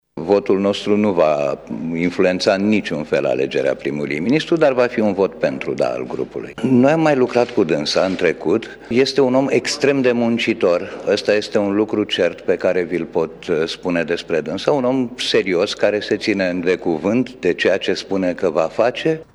Reprezentantul grupului minorităților în parlament, Varujan Pambuccian, a declarat, după discuțiile purtate cu șeful statului, că vor vota pentru ca Sevil Shhaideh să devină noul prim-ministru al României:
minoritati-declaratii.mp3